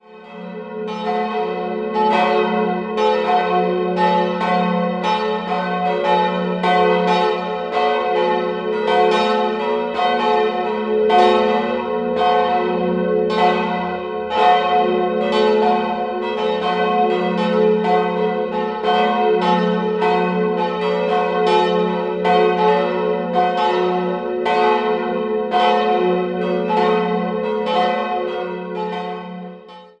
3-stimmiges TeDeum-Geläute: fis'-a'-h' Die kleine Glocke stammt noch aus der Erbauungszeit der Kirche und wurde 1930 bei Rincker gegossen. 1952 kamen die beiden anderen Gussstahlglocken des Bochumer Vereins auf den Turm.